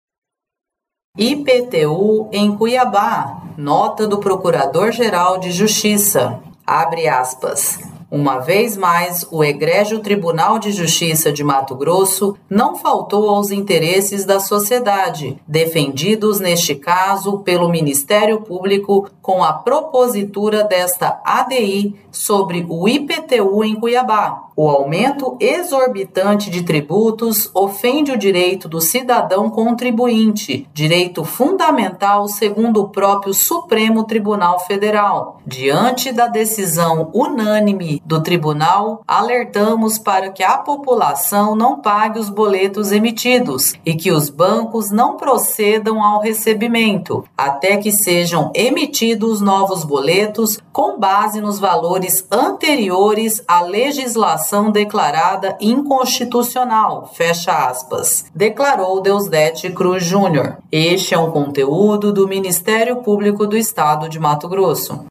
Nota do Procurador-Geral de Justiça